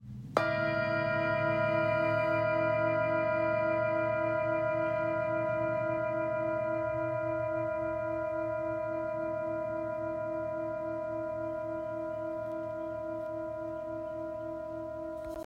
Etched Saraswati Handmade Singing Bowl – 21cm
Expertly hand-hammered by artisans, the bowl produces warm, resonant tones with long-lasting vibrations, designed to calm the mind and open space for clarity and inspiration. Its size allows for a deep, grounding sound that carries throughout a room.
Saraswati-21cm-new-stick.m4a